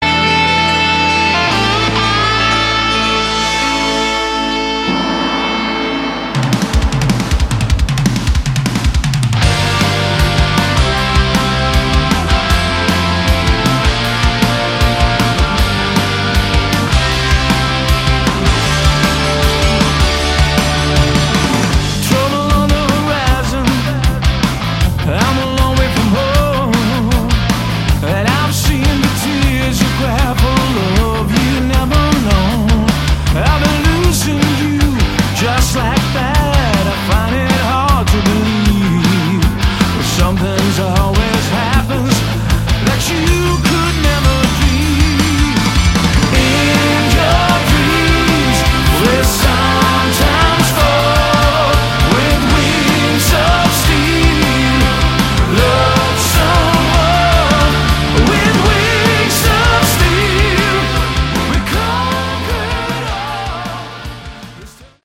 Category: Hard Rock
vocals, guitar, keyboards
bass
drums